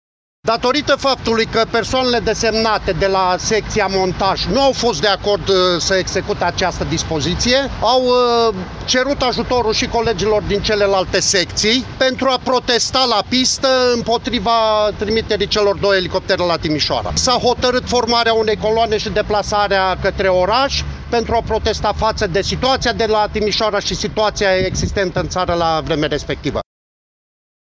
Unul dintre revoluționari